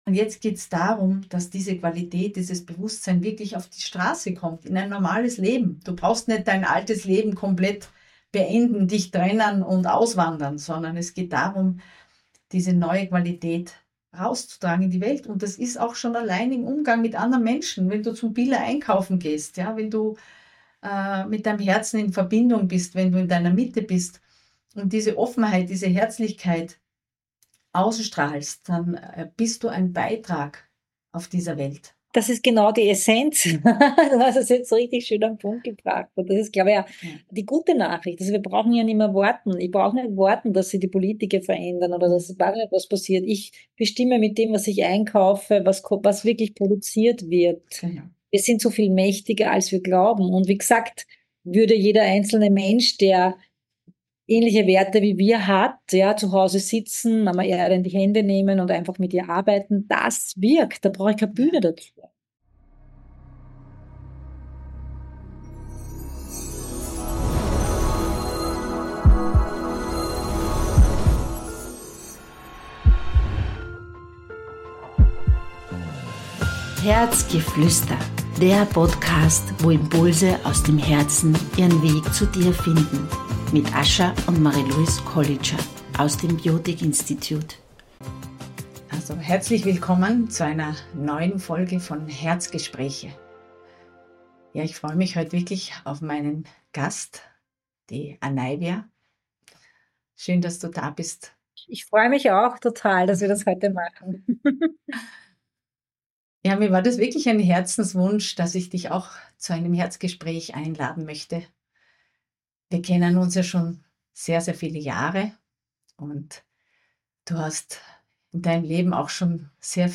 Dieses Gespräch lädt dazu ein, wieder bewusster hinzuhören, sich selbst Aufmerksamkeit zu schenken und den eigenen Körper als Wegweiser für Balance und Gesundheit wahrzunehmen.